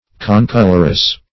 Search Result for " concolorous" : The Collaborative International Dictionary of English v.0.48: Concolorous \Con"col`or*ous\, a. (Zool.) Of the same color throughout.